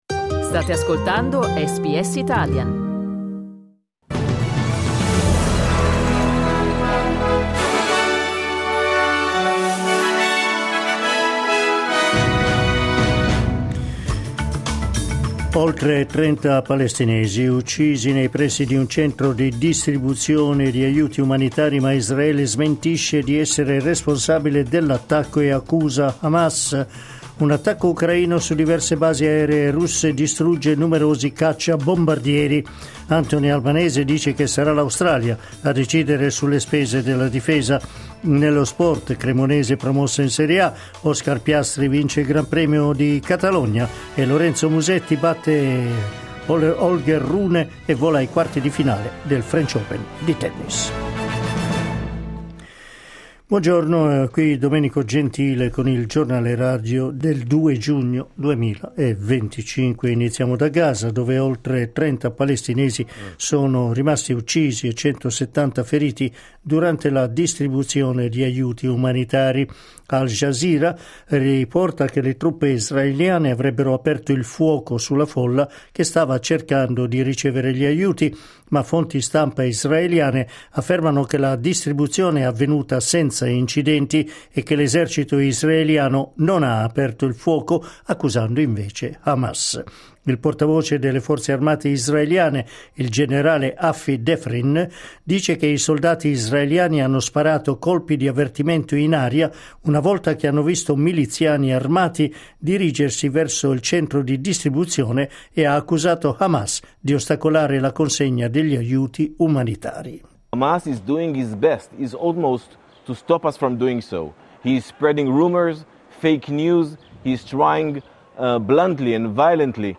Il notiziario di SBS in italiano.